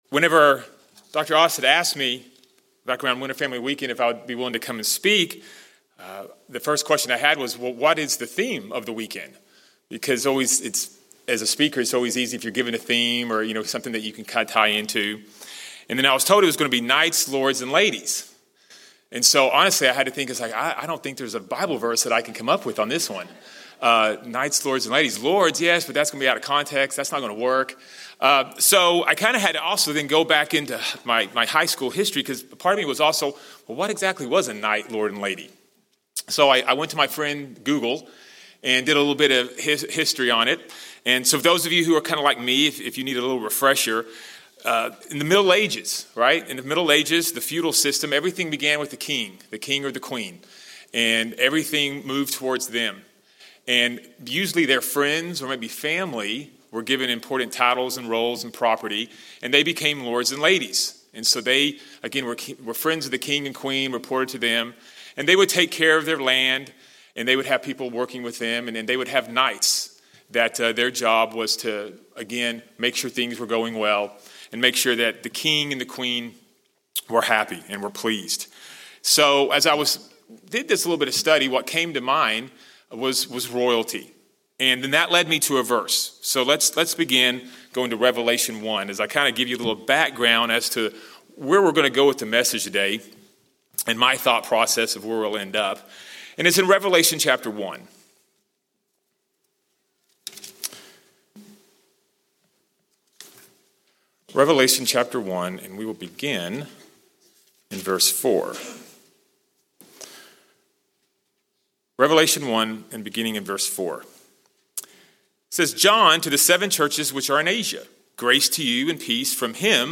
What can those being called expect to encounter during this lifelong race? This message provides some valuable insight into the race we all have before us. [Note - Given on a Sabbath in Atlanta prior to a Regional Prom held later that evening.]